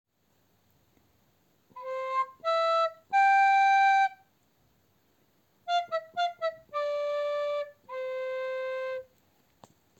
There are three binary rhythm time signatures : 2/4, 3/4 and 4/4.
TIME SIGNATURES